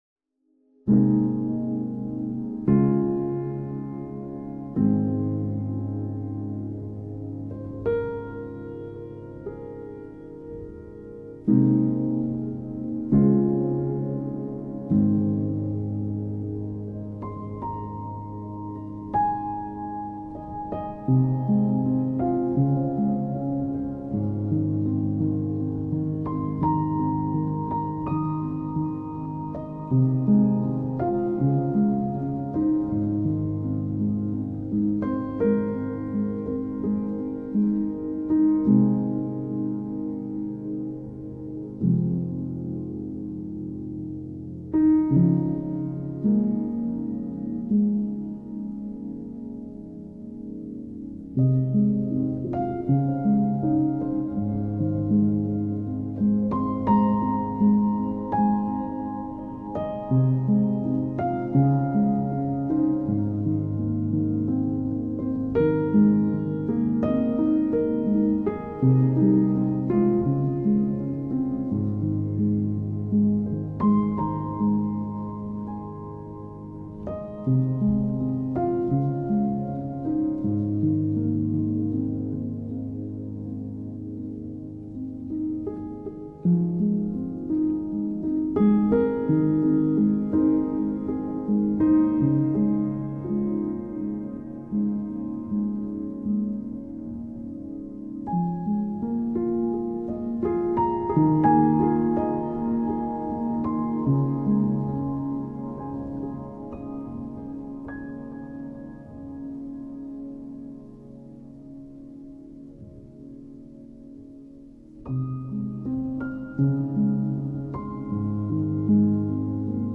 Classical Crossover
موسیقی بی کلام آرامبخش موسیقی بی کلام پیانو